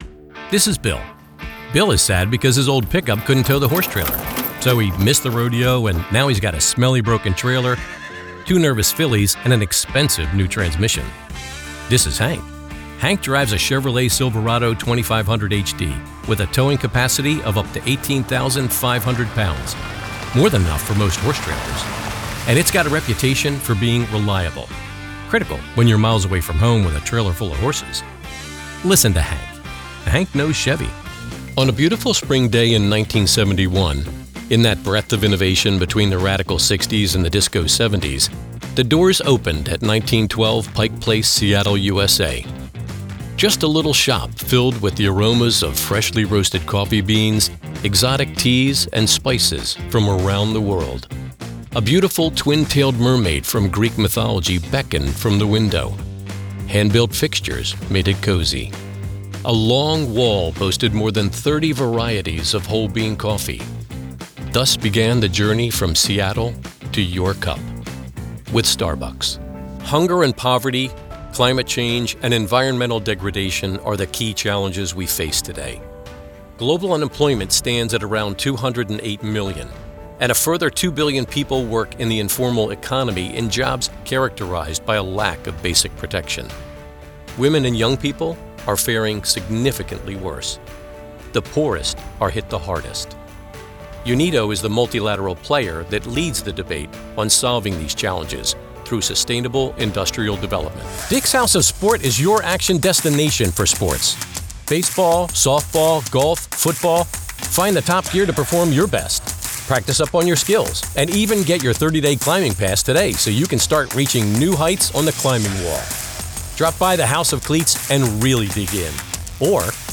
Explainer
• Sennheiser MKH416
• Professional Recording Booth
• Authentic, Approachable, Conversational and Friendly